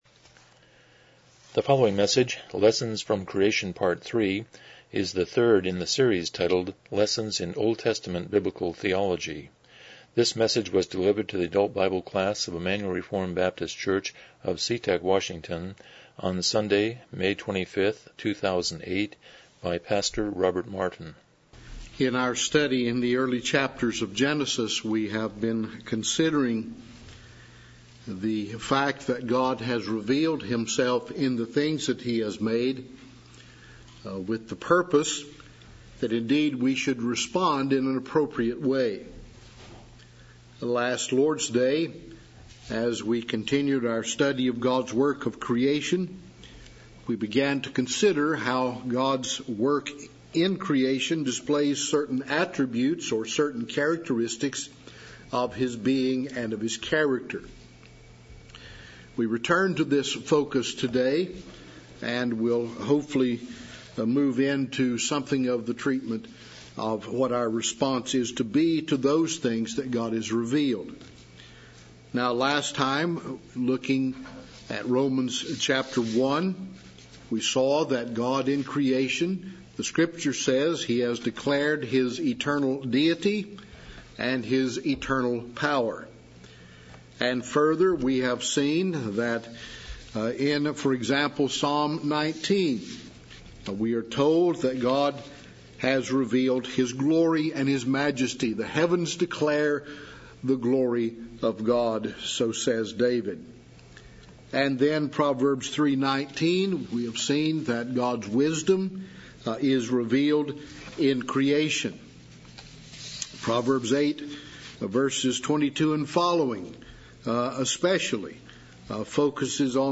Lessons in OT Biblical Theology Service Type: Sunday School « 30 Chapter 2.3